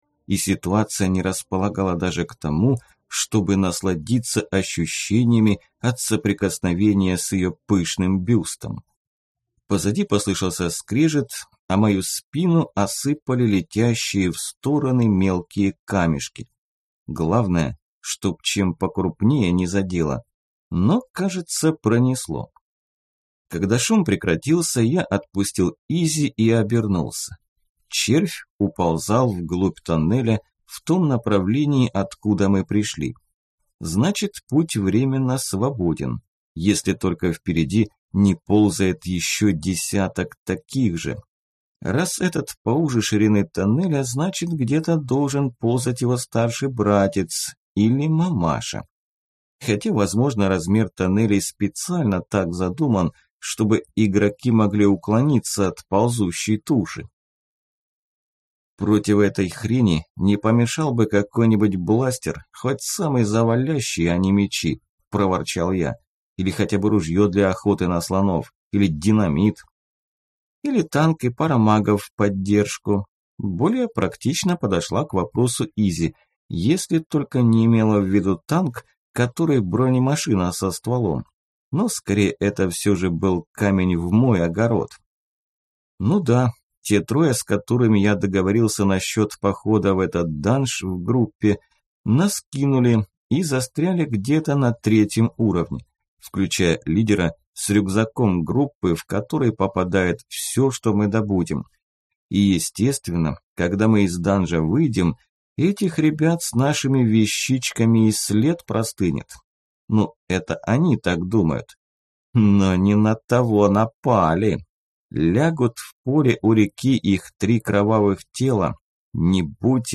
Аудиокнига Утраченный гримуар | Библиотека аудиокниг
Прослушать и бесплатно скачать фрагмент аудиокниги